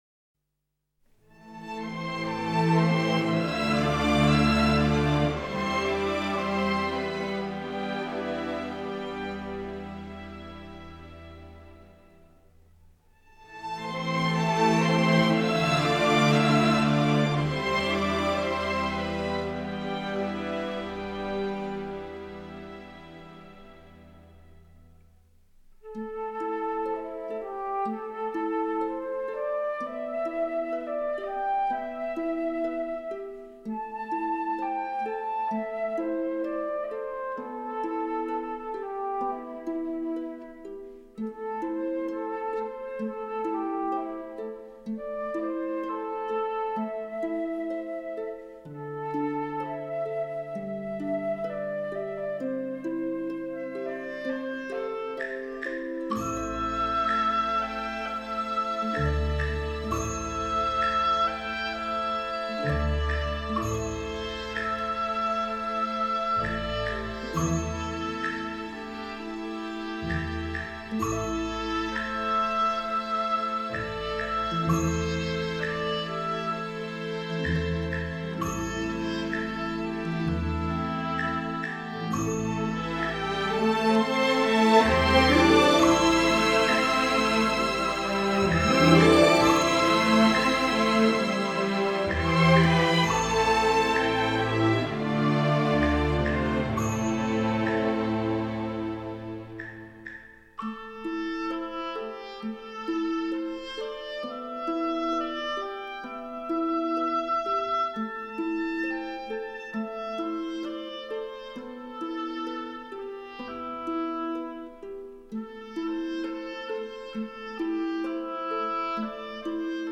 录音地点：北京农影制片厂录音棚